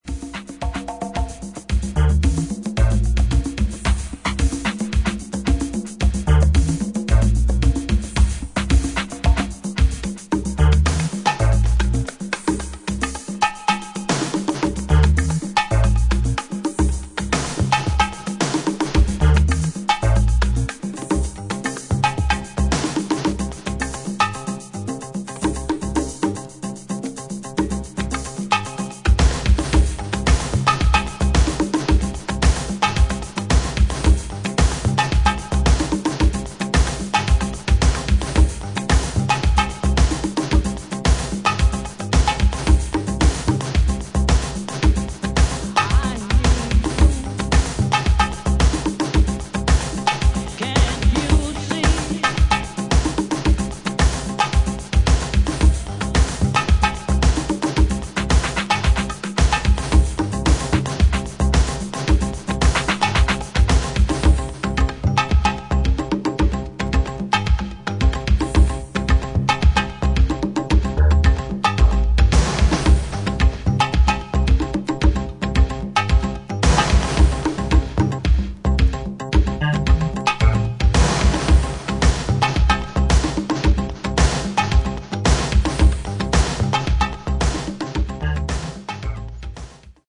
計3トラックをフロア仕様にエディットしたDJユース盤